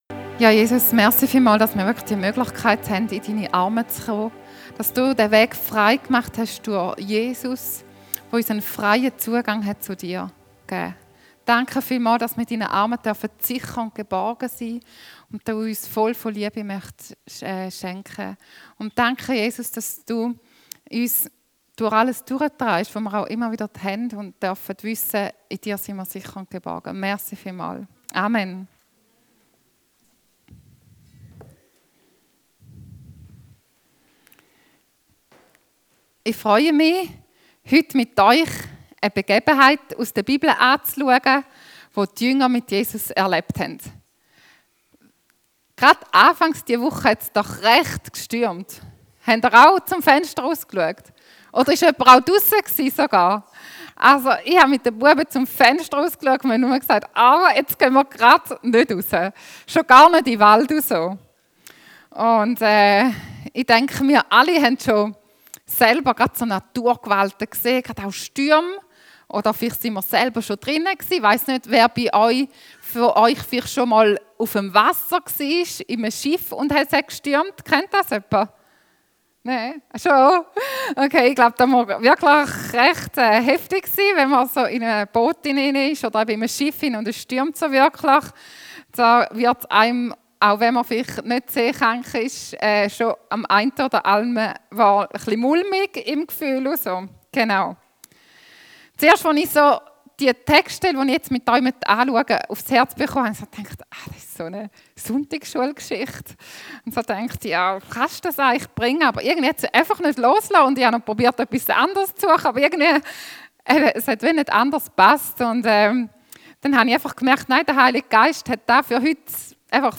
35-41 Dienstart: Gottesdienst Kernsatz